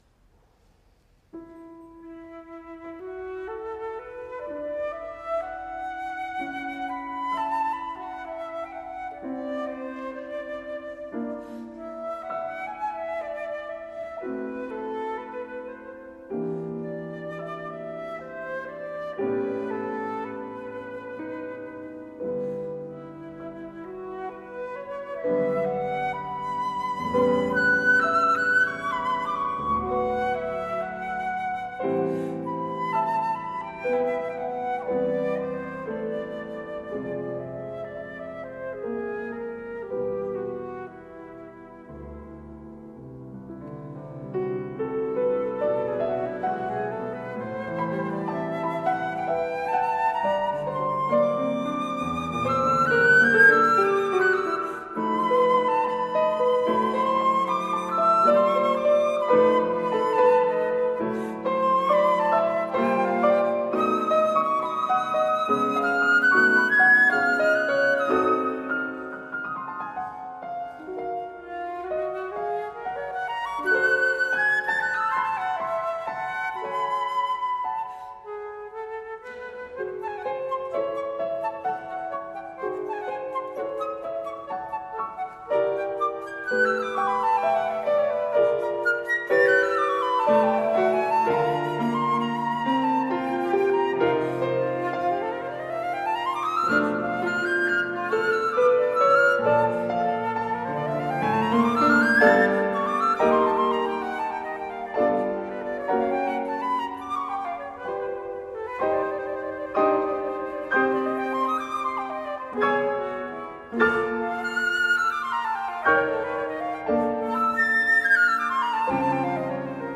flûte
piano